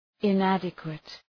Προφορά
{ın’ædəkwıt}